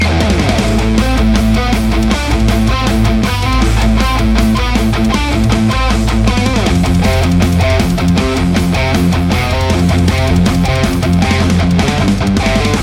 Metal Riff Mix
RAW AUDIO CLIPS ONLY, NO POST-PROCESSING EFFECTS